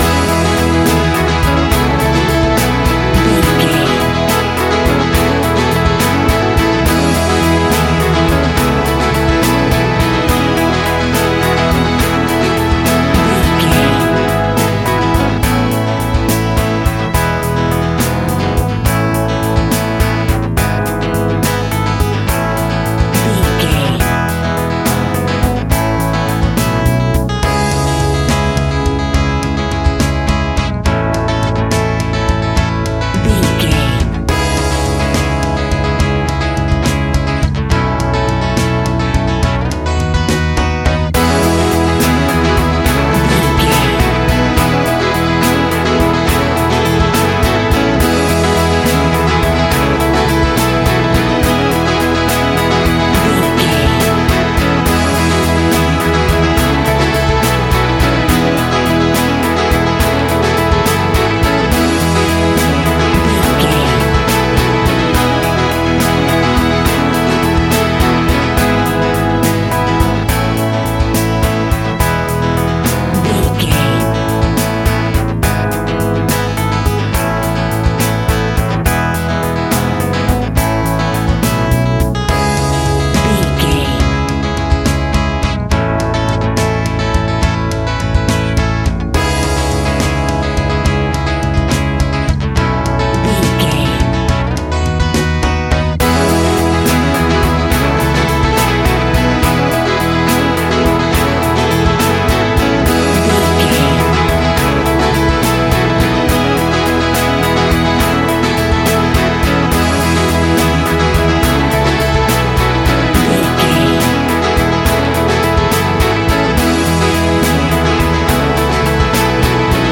Ionian/Major
pub rock
guitars
bass
drums
hammond organ